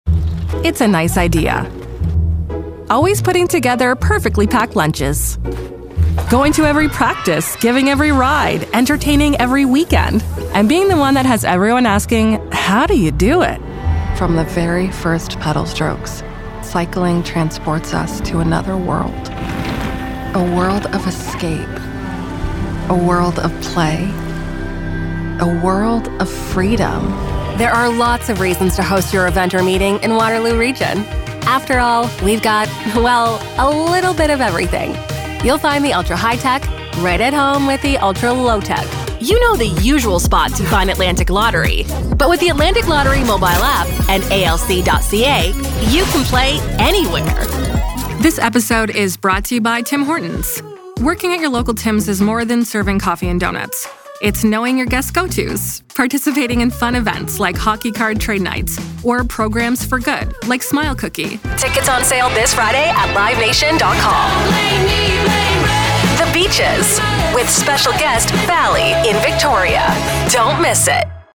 English (Canadian)
Warm
Natural
Approachable